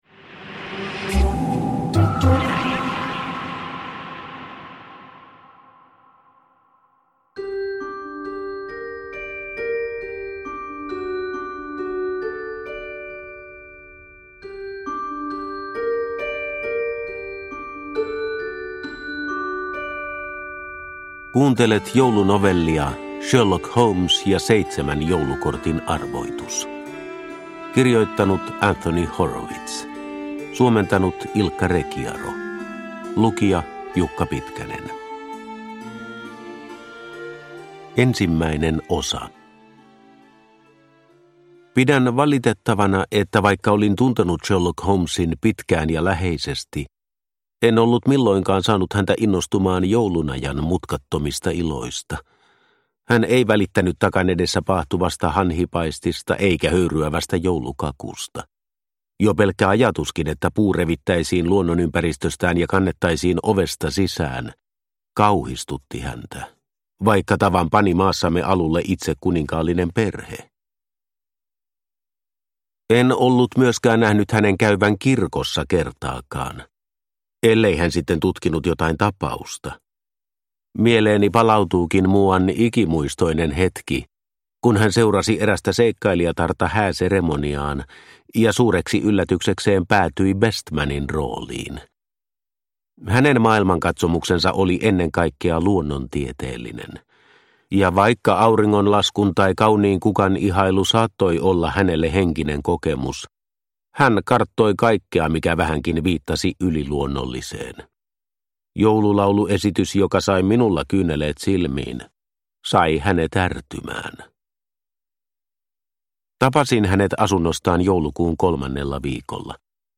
Sherlock Holmes ja seitsemän joulukortin arvoitus – Ljudbok – Laddas ner